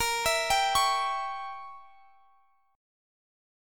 A#dim7 Chord
Listen to A#dim7 strummed